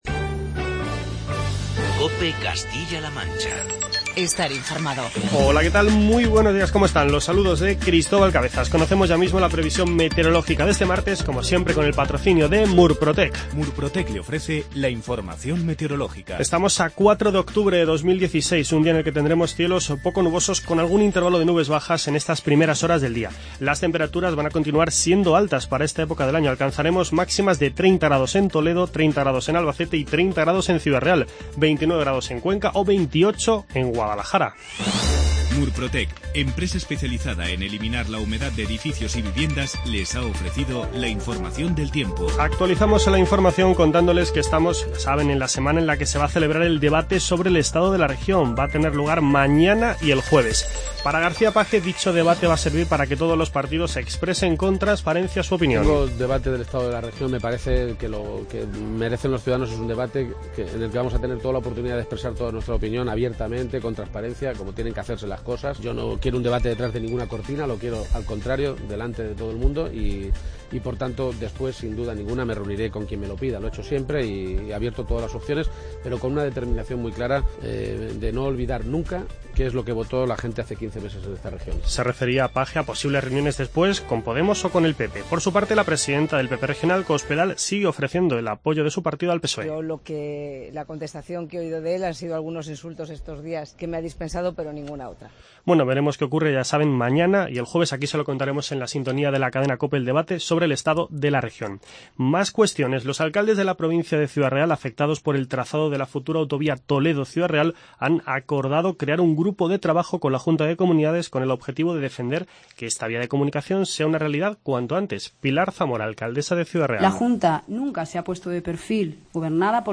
Informativo regional
AUDIO: Comenzamos este espacio con las declaraciones de Emiliano García-Page y María Dolores Cospedal.